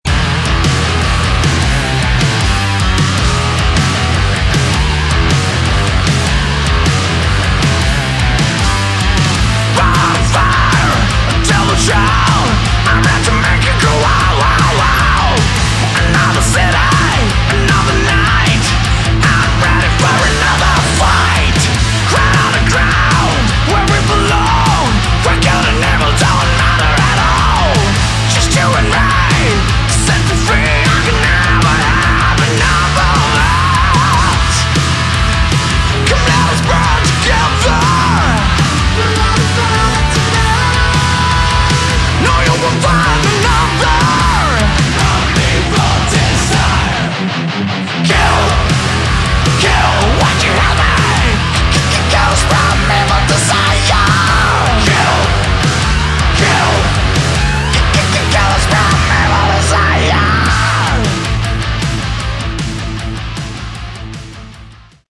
Category: Hard Rock
vocals, rhythm guitars
bass, keyboards
lead guitars
drums